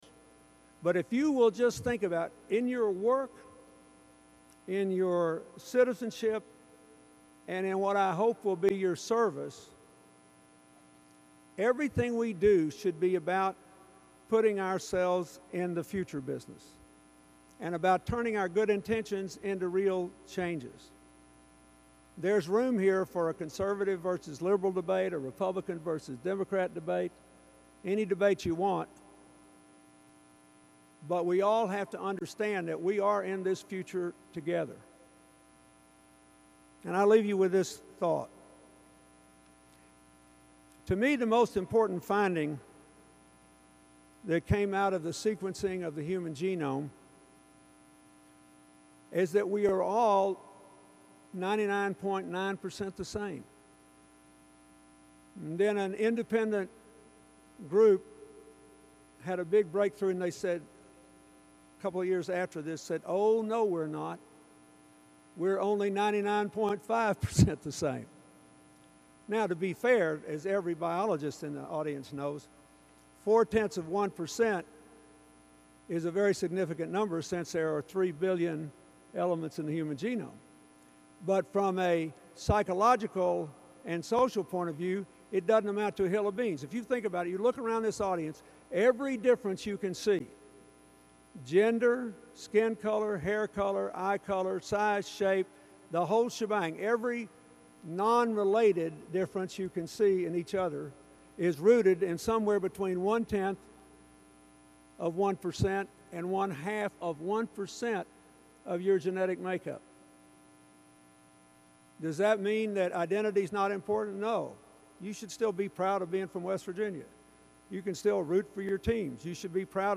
Former President Bill Clinton told graduates at West Virginia University Sunday (May 16) they need to be “in the future business.”
Click below to hear Bill Clinton talk about the connectedness of the future: